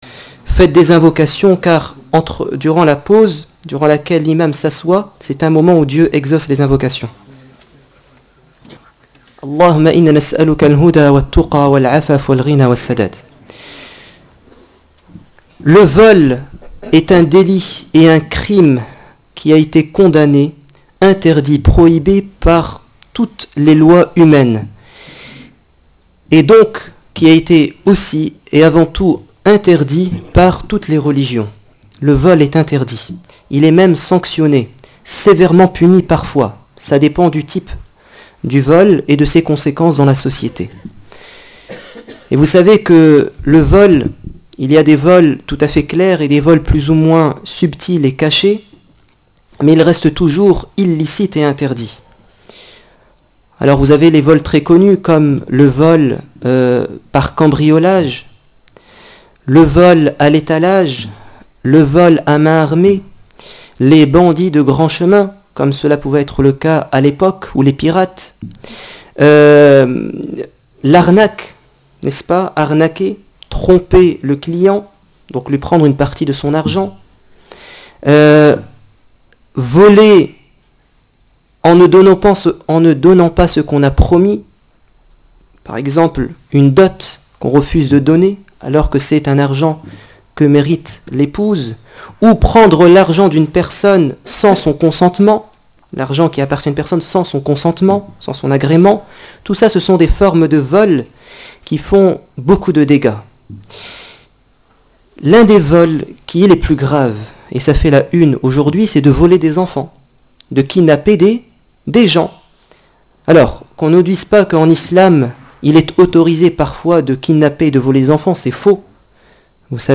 Le discours du vendredi 16 mai 2014 à la mosquée de Raismes